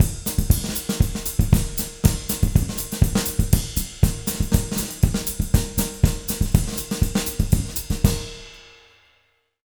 240SAMBA03-R.wav